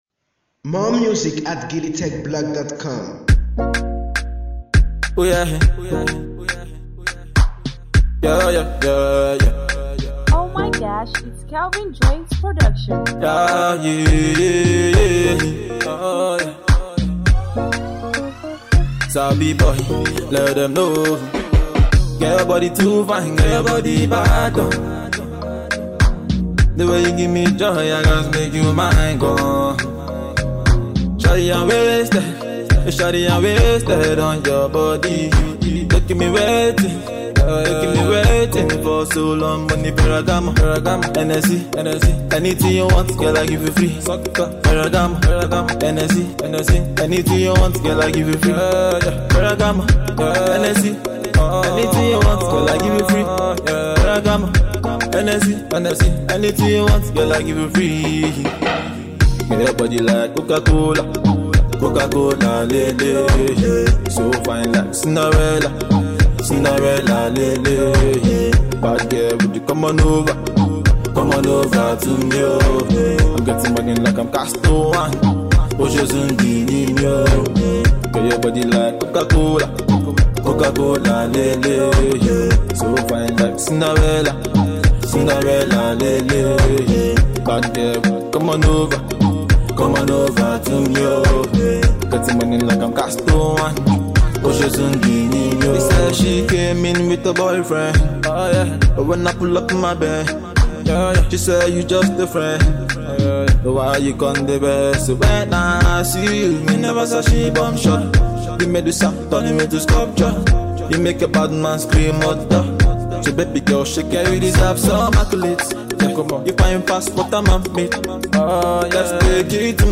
an afro pop single with cool vibes